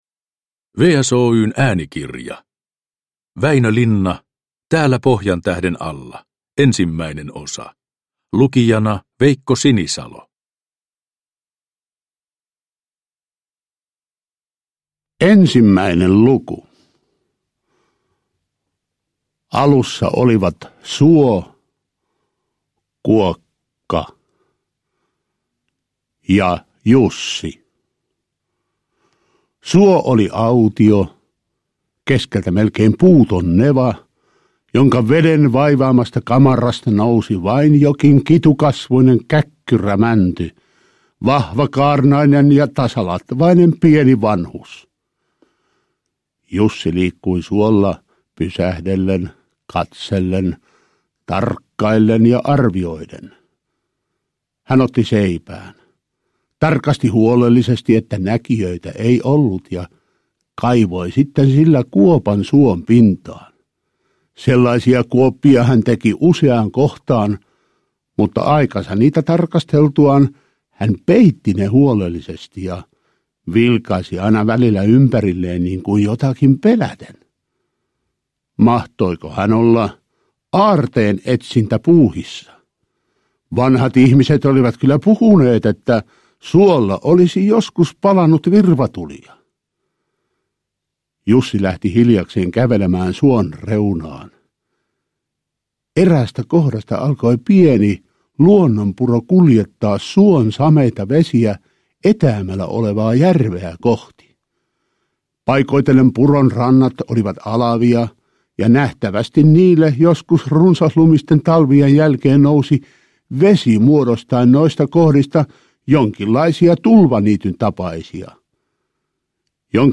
Täällä Pohjantähden alla 1 – Ljudbok
Äänikirjan tulkitsee Veikko Sinisalo.
Uppläsare: Veikko Sinisalo